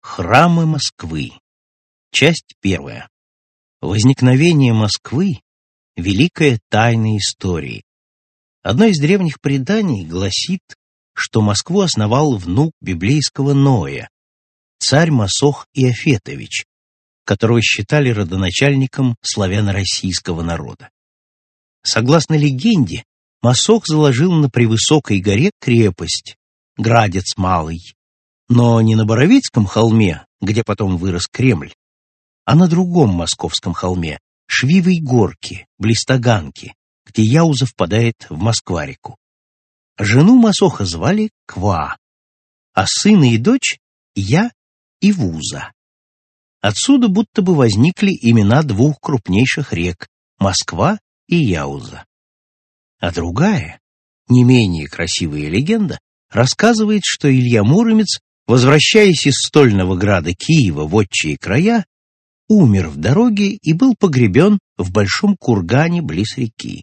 Аудиокнига Православные святыни Московского Кремля. Маршрут паломника | Библиотека аудиокниг